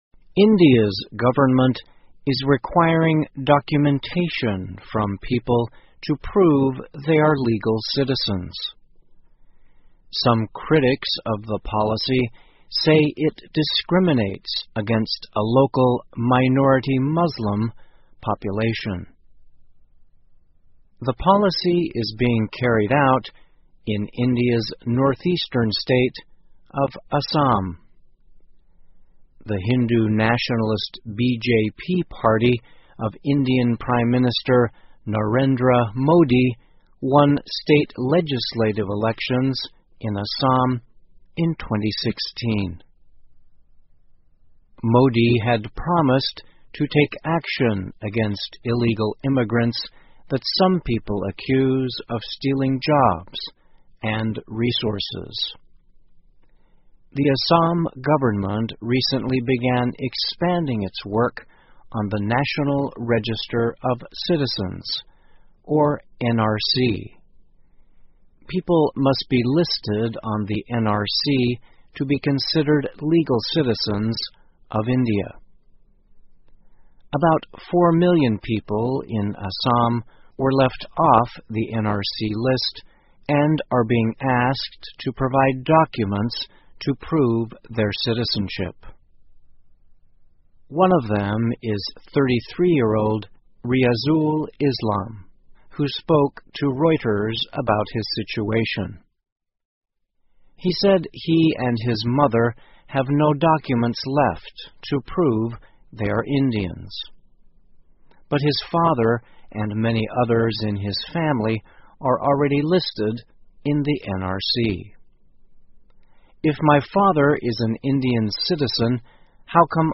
VOA慢速英语--Critics Say India Citizenship Test Unfairly Targets Muslims 听力文件下载—在线英语听力室